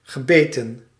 Ääntäminen
Synonyymit kwaad boos Ääntäminen Tuntematon aksentti: IPA: /ɣəˈbeːtə(n)/ Haettu sana löytyi näillä lähdekielillä: hollanti Käännöksiä ei löytynyt valitulle kohdekielelle.